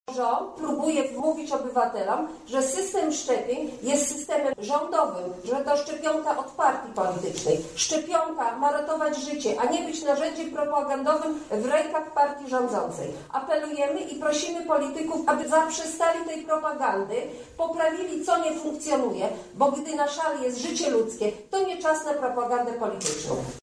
Narodowy program szczepień nie funkcjonuje, to nieudolny program, tak jak cała polityka rządu związana z epidemią koronawirusa– mówi Posłanka Koalicji Obywatelskiej Marta Wcisło: